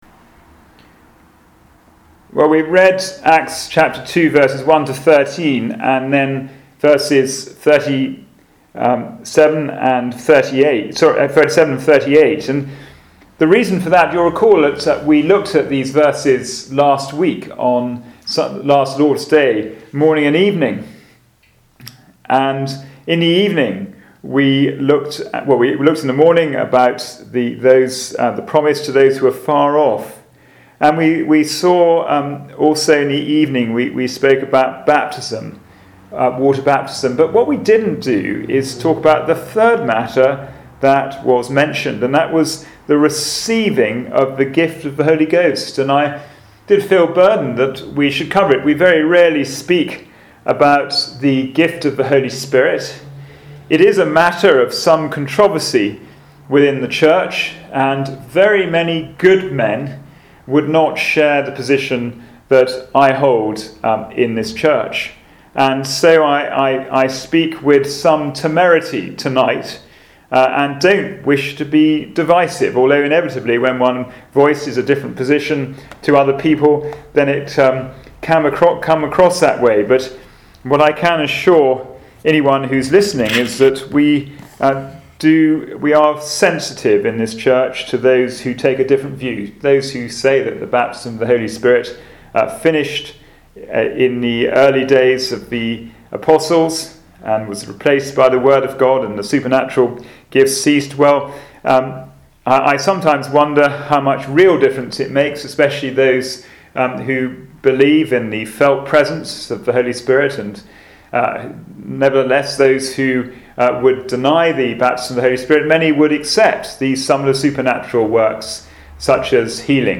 Passage: Acts 2:1-13, 38-39 Service Type: Sunday Evening Service